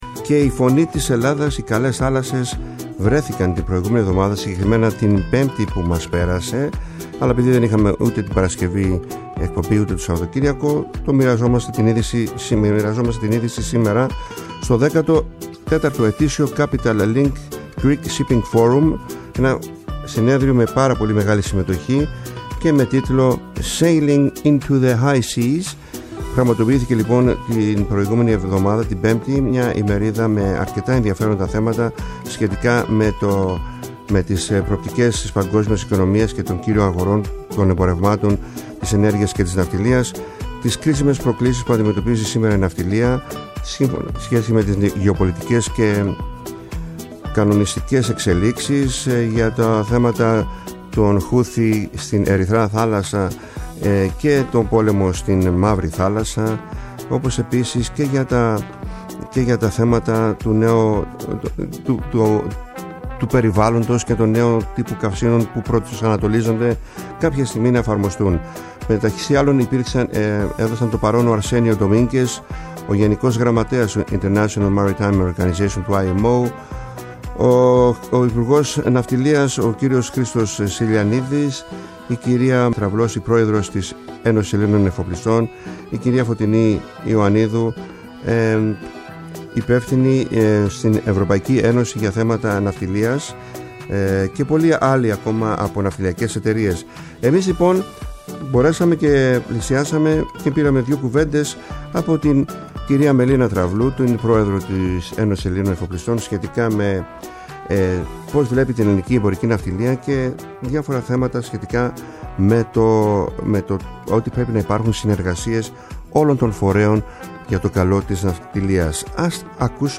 βρέθηκε στο 14ο ναυτιλιακό συνέδριο  Capital Link  που διοργανώνεται στην Αθήνα